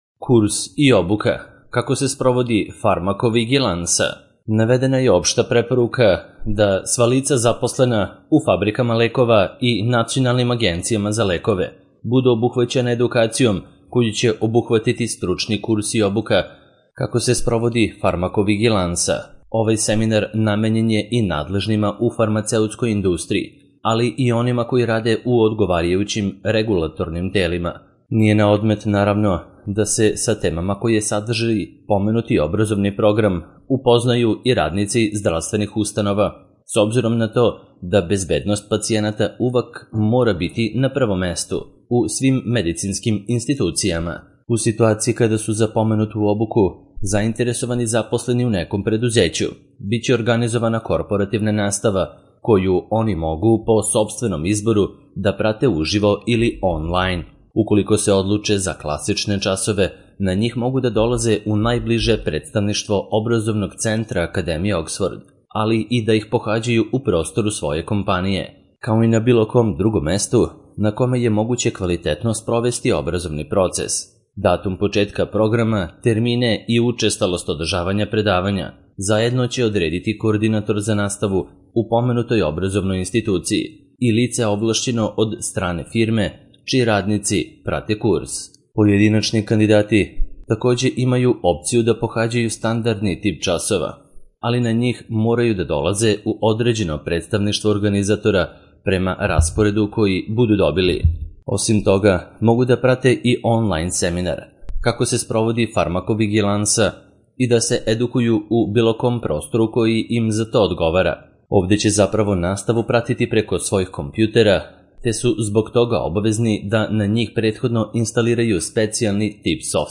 Kako se sprovodi farmakovigilansa - Audio verzija